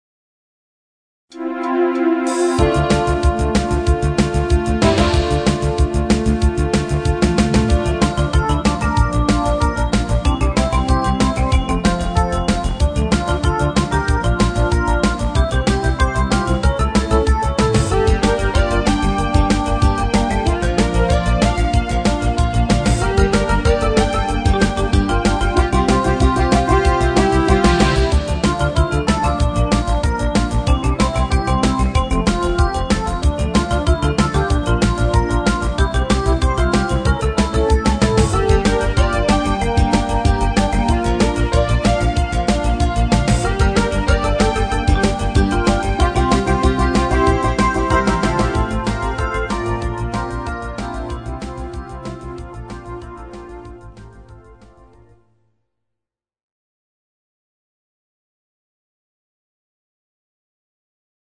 Genre(s): Deutschpop  Partyhits  |  Rhythmus-Style: Fastbeat